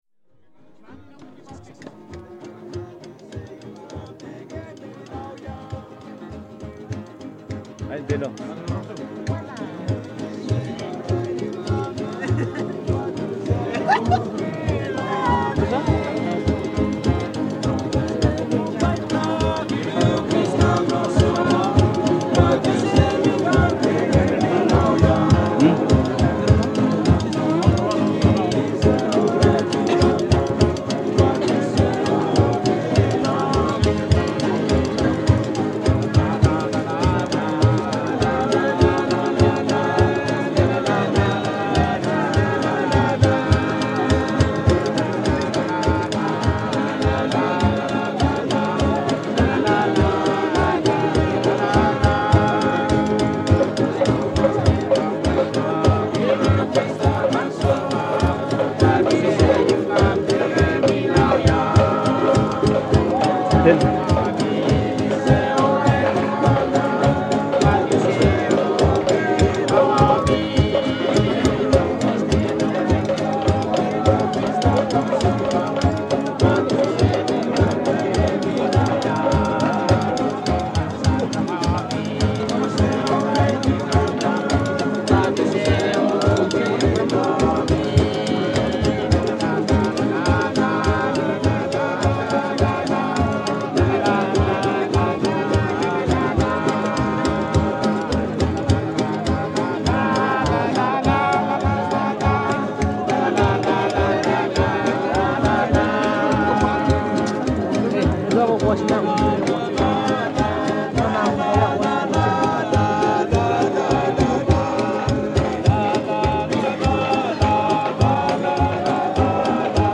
South Pacific "pop" music
South Pacific "pop" music recorded on the beach with full band, including guitar, tea chest bass and spoons.
From the sound collections of the Pitt Rivers Museum, University of Oxford